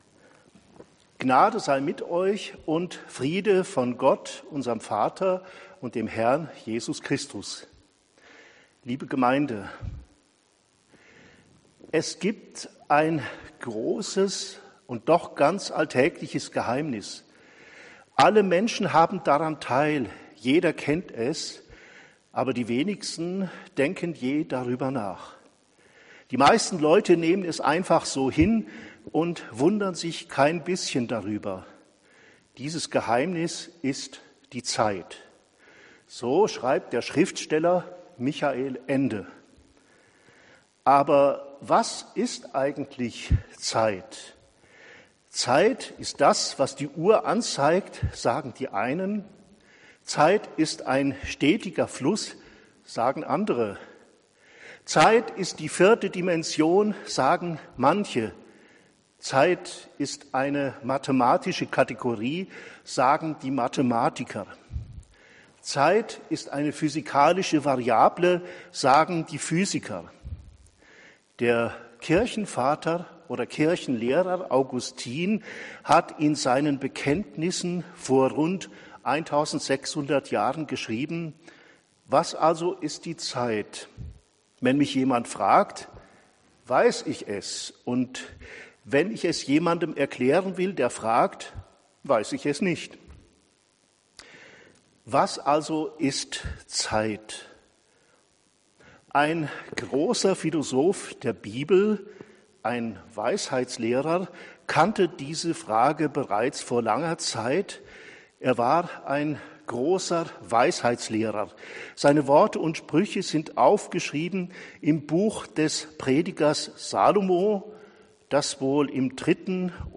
Abschiedspredigt